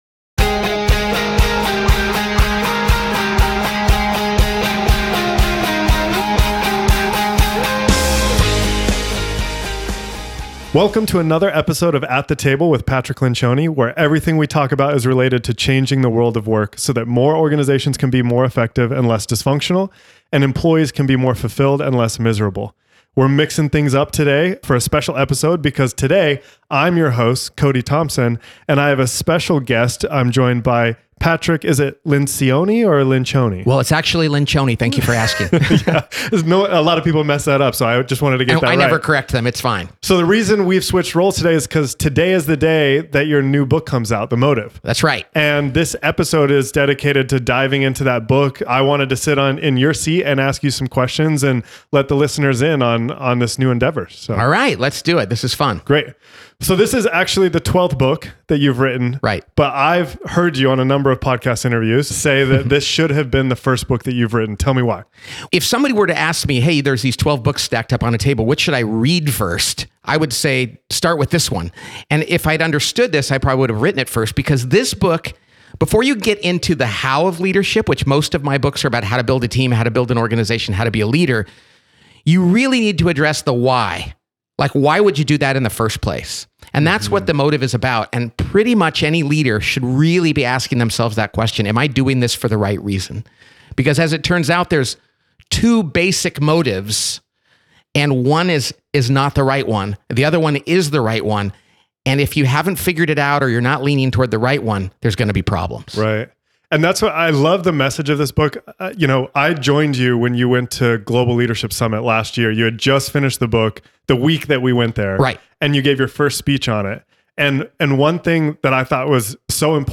Play Rate Listened List Bookmark Get this podcast via API From The Podcast 1 2 Real conversations and practical advice for everyday leaders.